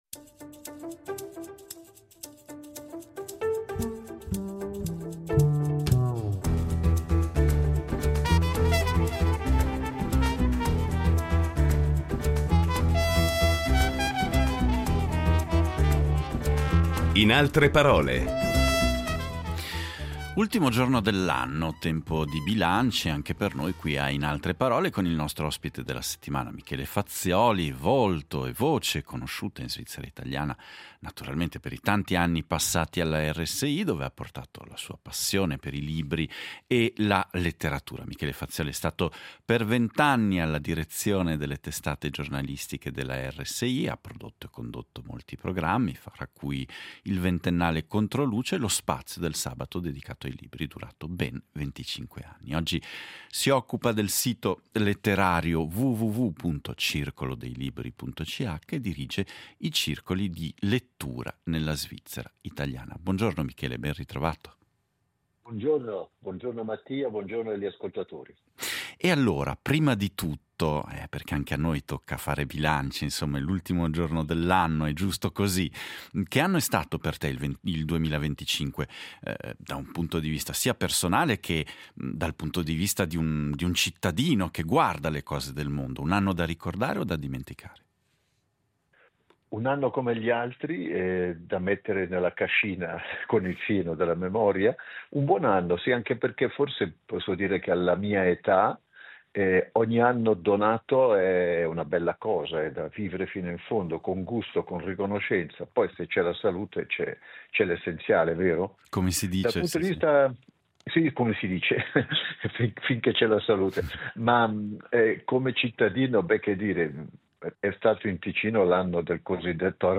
Incontro con il giornalista ed esperto di narrativa